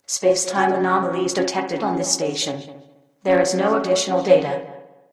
Makes the AI's voice more "AI"-like, adds a title screen, enables end of